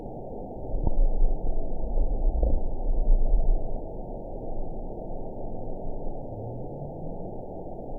event 922393 date 12/30/24 time 08:14:21 GMT (11 months ago) score 8.62 location TSS-AB10 detected by nrw target species NRW annotations +NRW Spectrogram: Frequency (kHz) vs. Time (s) audio not available .wav